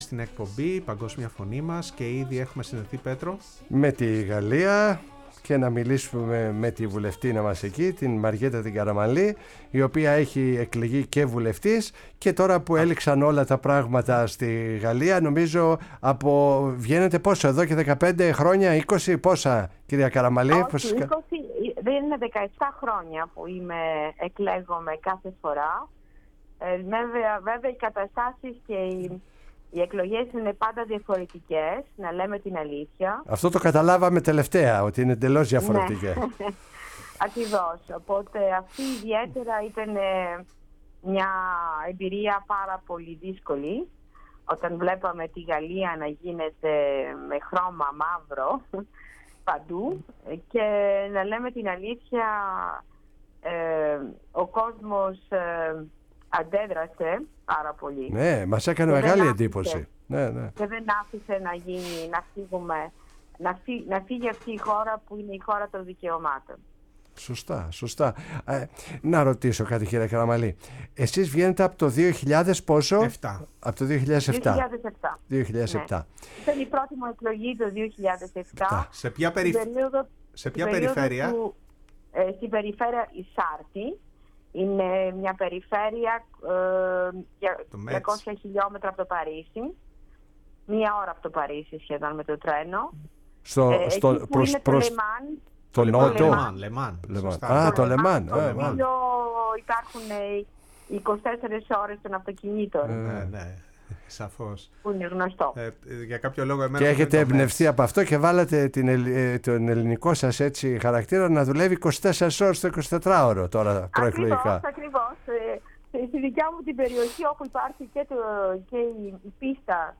H επανεκλεγείσα βουλευτής Μαρ. Καραμανλή στη Γαλλία μιλάει στην εκπομπή “Η Παγκόσμια Φωνή μας”|08.07.2024
Η ΦΩΝΗ ΤΗΣ ΕΛΛΑΔΑΣ Η Παγκοσμια Φωνη μας ΟΜΟΓΕΝΕΙΑ ΣΥΝΕΝΤΕΥΞΕΙΣ Συνεντεύξεις ΓΑΛΛΙΑ κοινοβουλευτικες εκλογες Μαριεττα Καραμανλη Μαριν Λε Πεν Νεο Λαικο Μετωπο Προεδρος Μακρον